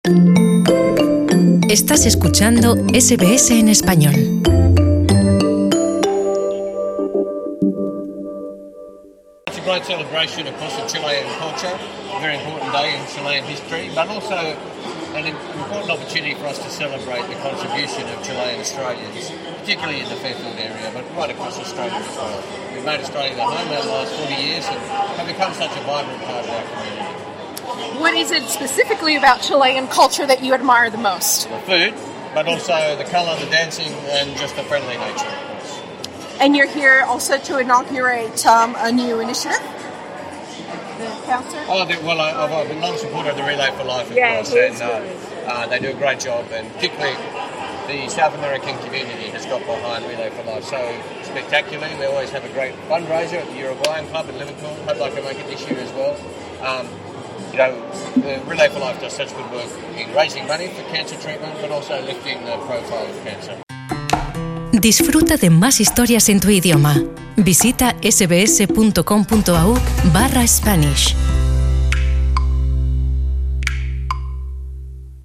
Los chilenos, mexicanos y centroamericanos en Australia celebraron sus respectivas fiestas patrias en el país, con música, comida, alegría, pero sobre todo con mucho orgullo y un despliegue de su identidad nacional. SBS Spanish tuvo el honor de compartir con algunos miembros de la comunidad en el Fairfield Showground, en el oeste de Sídney.